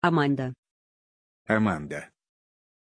Pronuncia di Amanda
pronunciation-amanda-ru.mp3